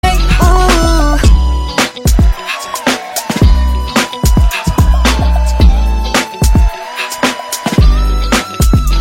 KSHMR_Vocal_Words_-_Hey_Ho_Crowd_Chant